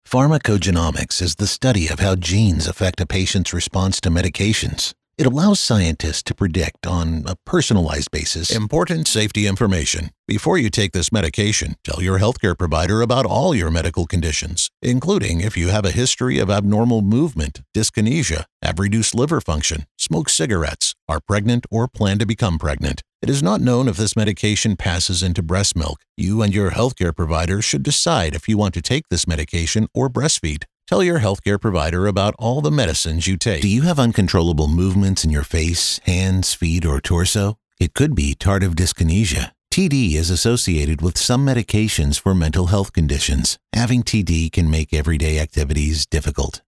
Male
English (North American)
Yng Adult (18-29), Adult (30-50)
Medical Narrations
Medical Narration
Words that describe my voice are Real, Fun, Friendly.